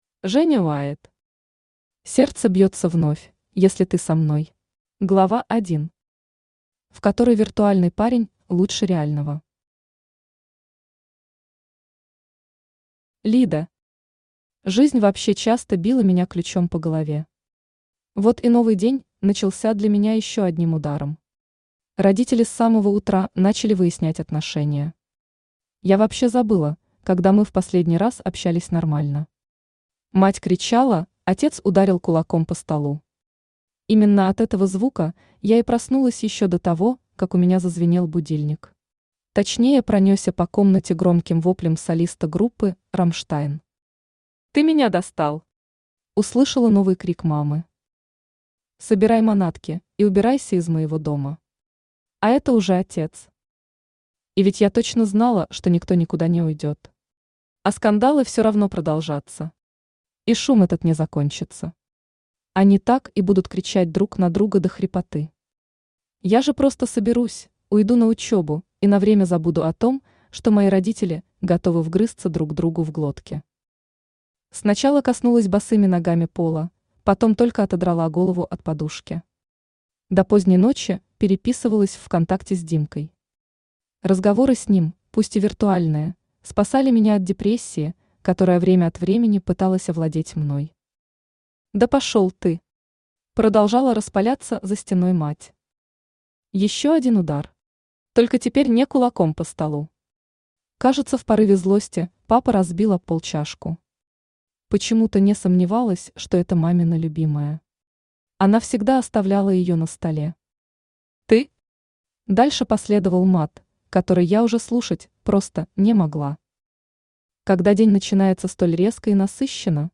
Аудиокнига Сердце бьется вновь, если ты со мной | Библиотека аудиокниг
Aудиокнига Сердце бьется вновь, если ты со мной Автор Женя Уайт Читает аудиокнигу Авточтец ЛитРес.